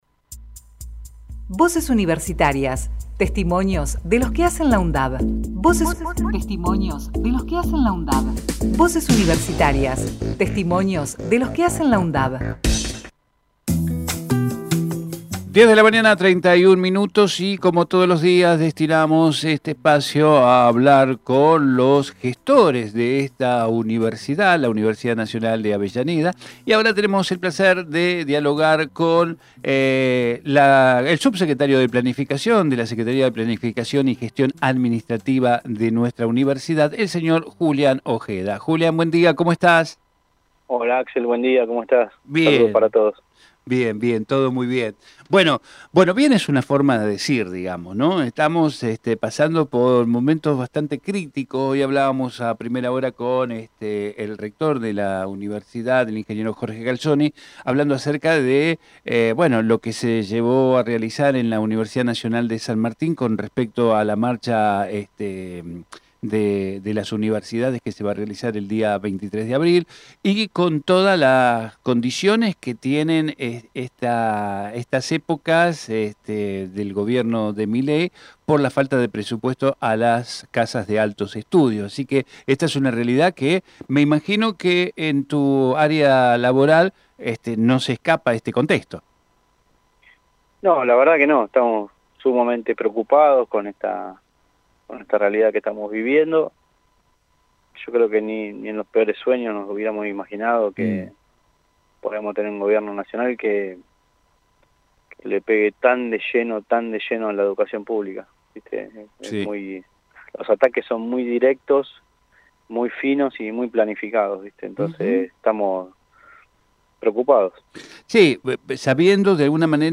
TERRITORIO SUR - JULIAN OJEDA CALZONI Texto de la nota: Compartimos con ustedes la entrevista realizada en Territorio Sur con el Subsecretario de Planificación y Administración, Julián Ojeda Calzoni Archivo de audio: TERRITORIO SUR - JULIAN OJEDA CALZONI Programa: Territorio Sur